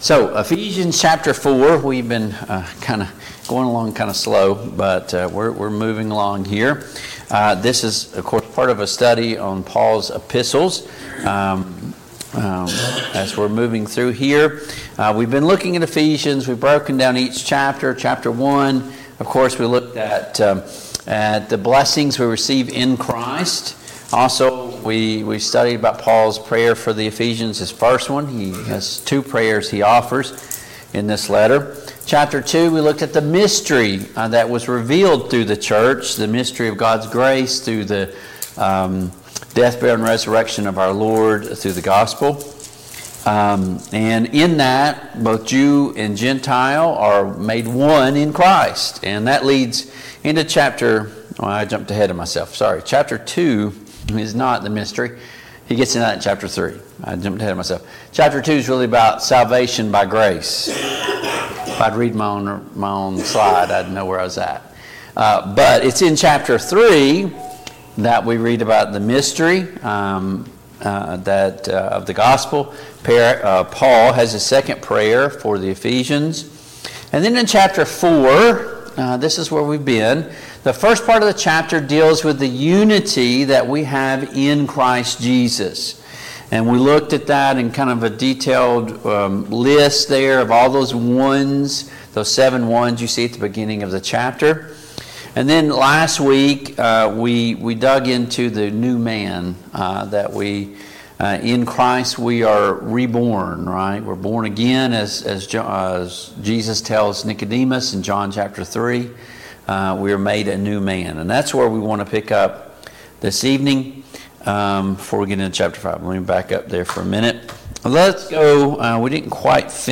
Passage: Ephesians 4:17-32, Ephesians 5:1-17 Service Type: Mid-Week Bible Study